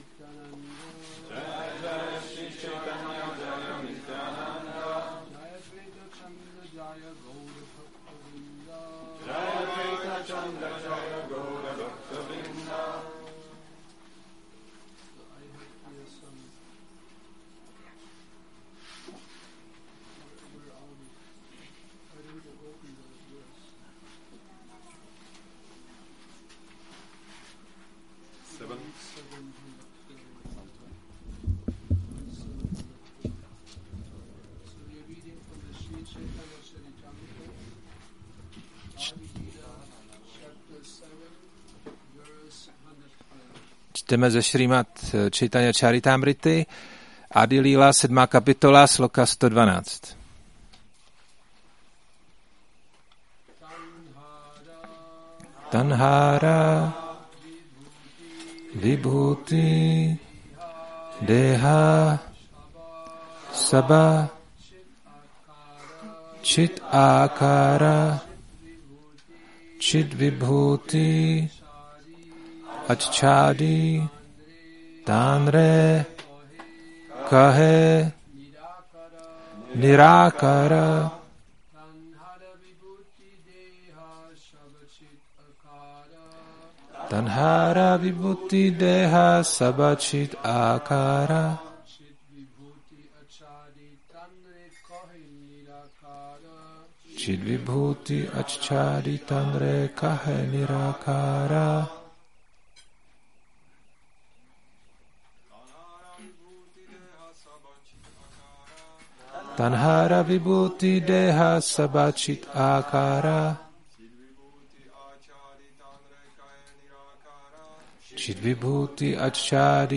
Šrí Šrí Nitái Navadvípačandra mandir
Přednáška CC-ADI-7.112